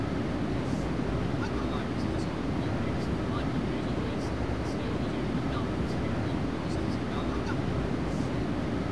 trainamb.ogg